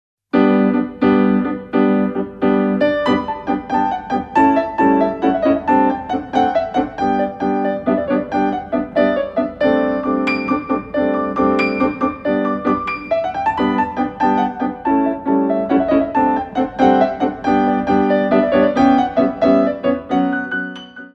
Petit allegro 1
6/8 - 12x8